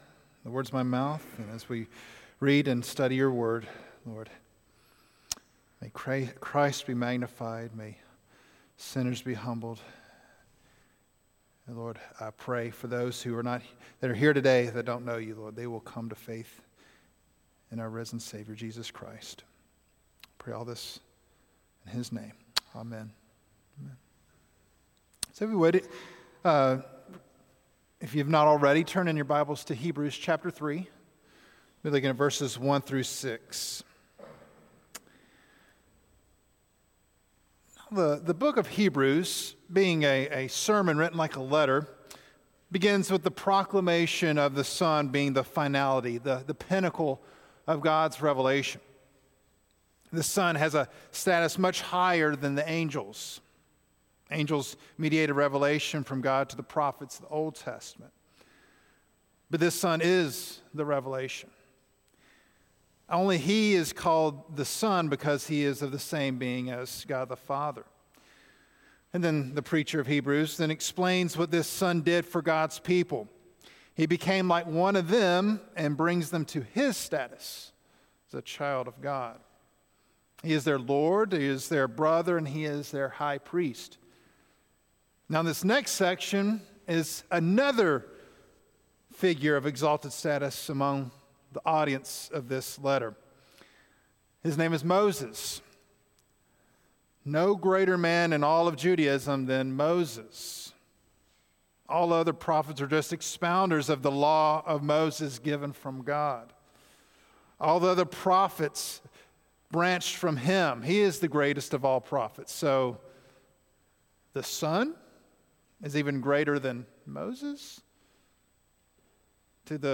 Weekly Sermons from Providence Baptist Church in Huntsville Alabama